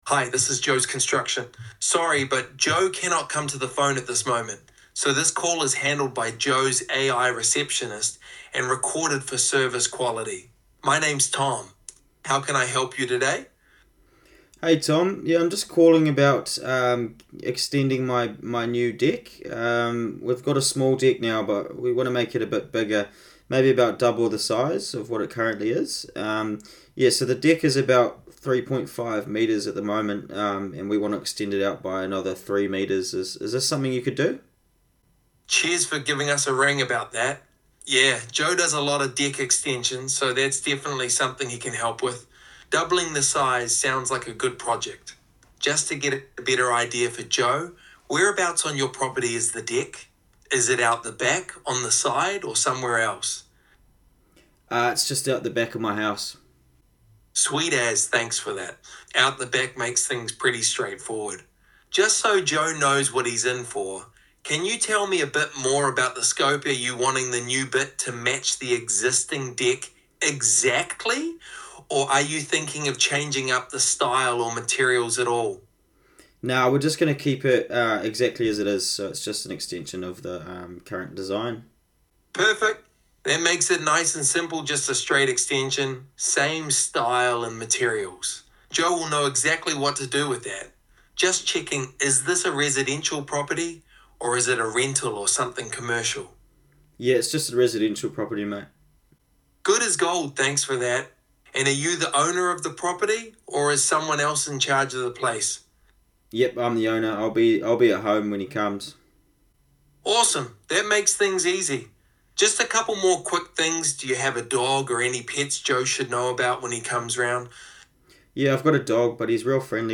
Sounds Completely Human
🎧 Customer inquiry – Deck extension project